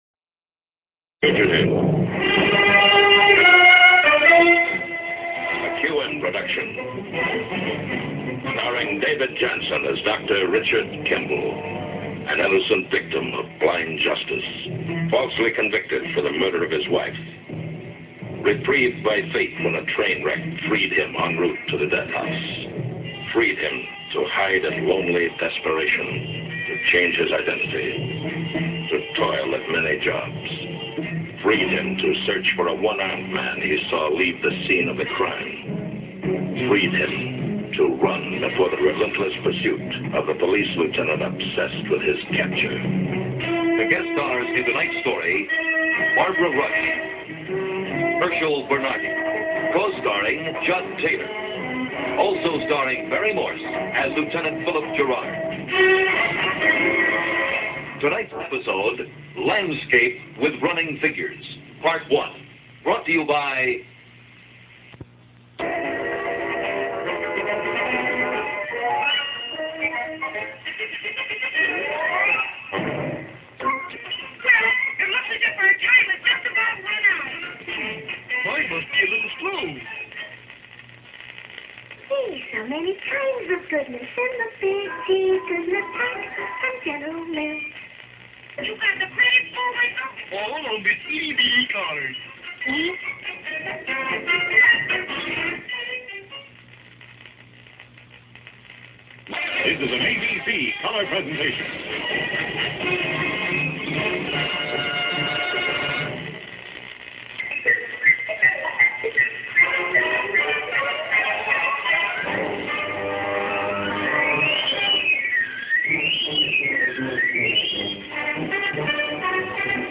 Recorded on a reel to reel tape recorder dad bought in 1965
TV two recording from 1965.